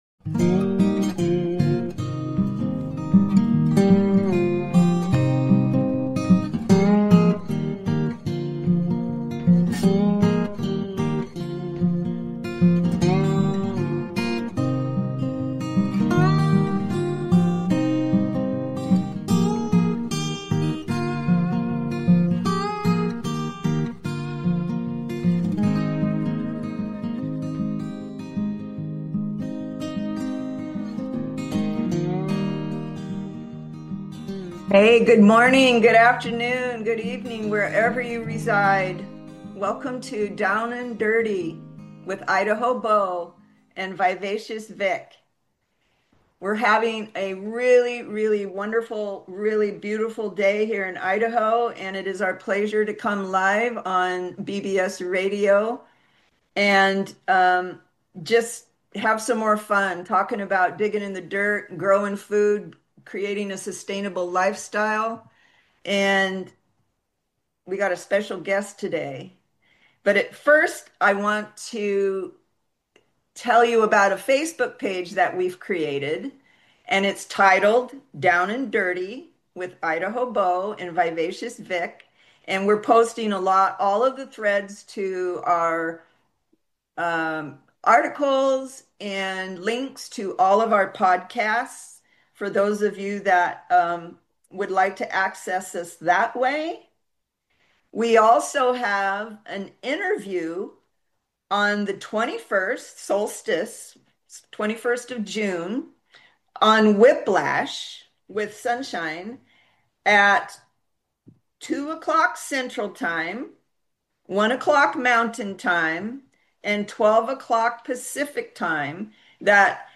We are going live this Sunday & will be taking calls.